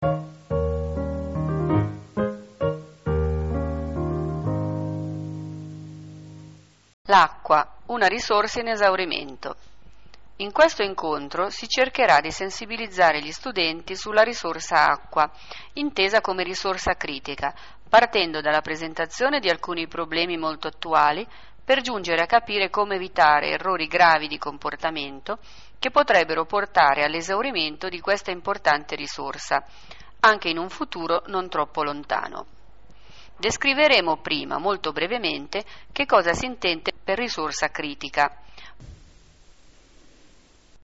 Si tratta di una serie radiofonica composta da lezioni audio che i ragazzi possono ascoltare, scaricare da internet (anche in automatico), mettere sui loro i-pod, risentirle quando, dove e come vogliono.